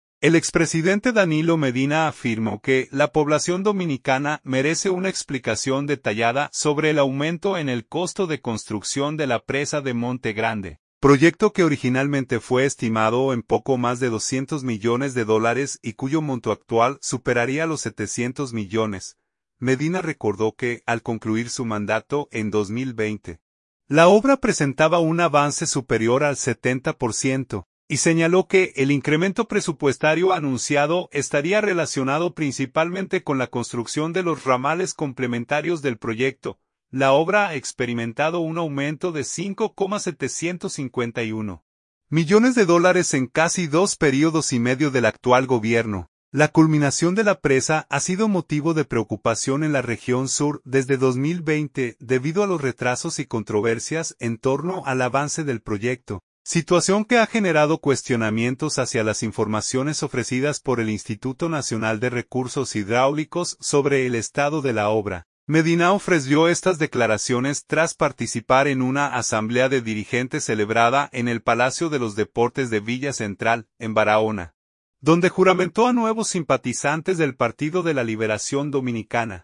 Medinao freció estas declaraciones tras participar en una asamblea de dirigentes celebrada en el Palacio de los Deportes de Villa Central, en Barahona, donde juramentó a nuevos simpatizantes del Partido de la Liberación Dominicana.